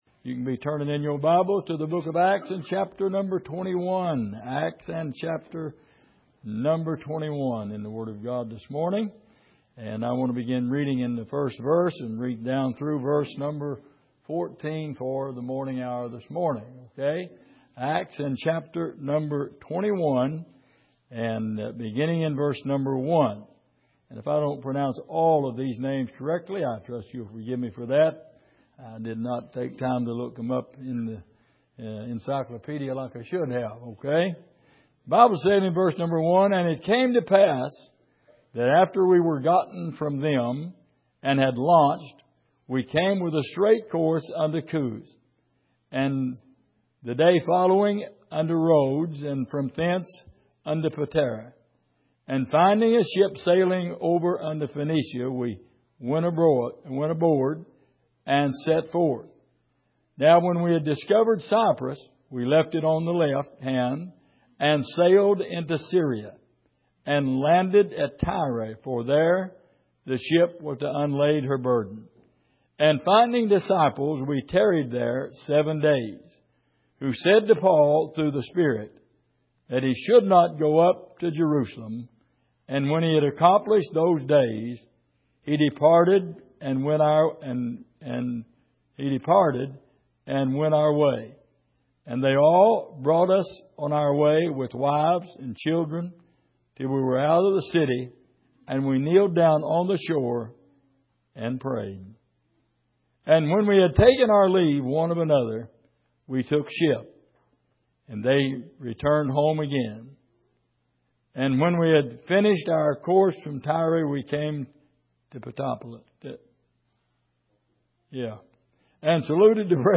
Passage: Acts 21:1-14 Service: Sunday Morning